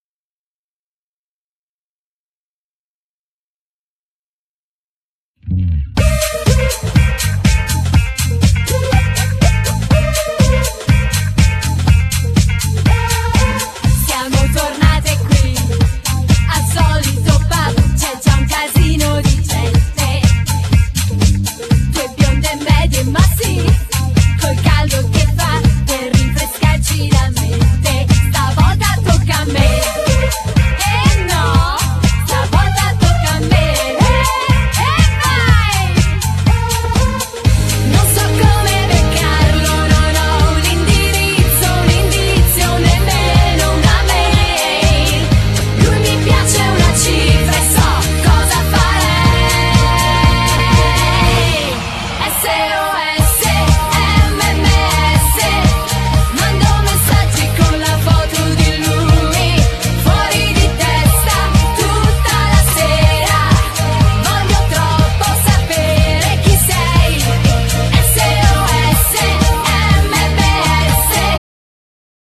Genere : Pop
Motivetto estivo con aspirazione del tormentone!!!!